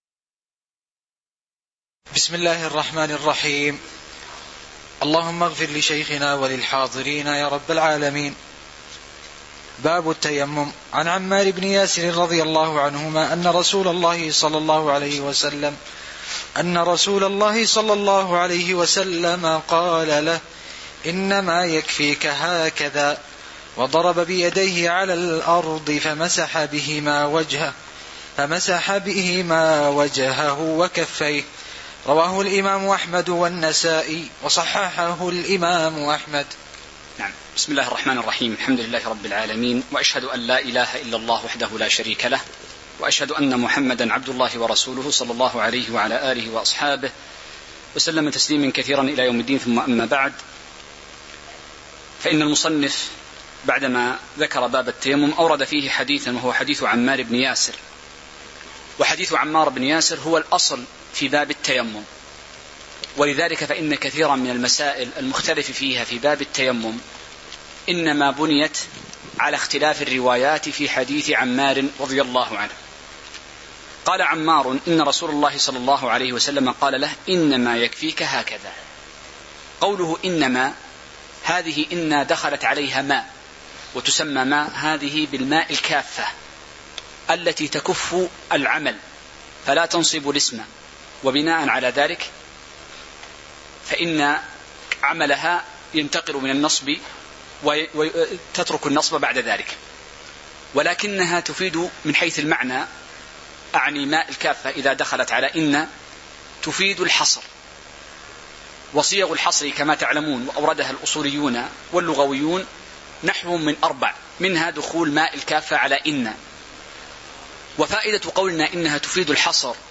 تاريخ النشر ٢٤ جمادى الآخرة ١٤٤٠ هـ المكان: المسجد النبوي الشيخ